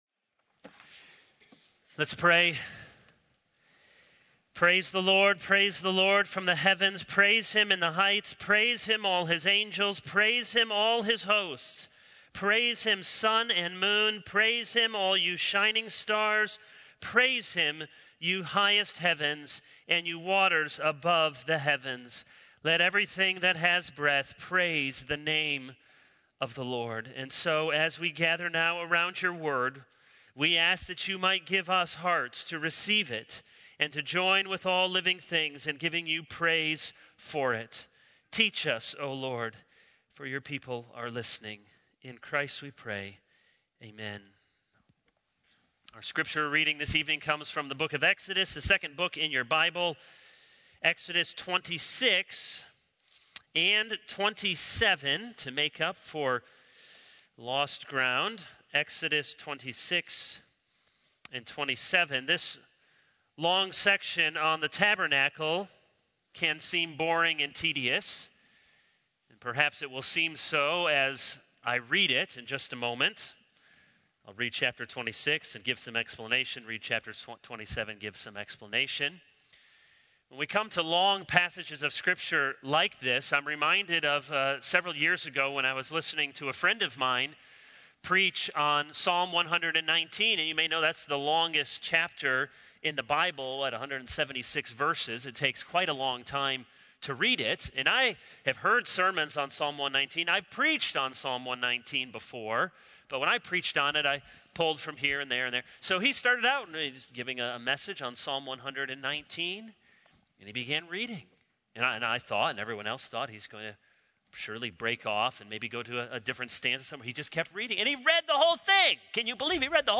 This is a sermon on Exodus 26-27.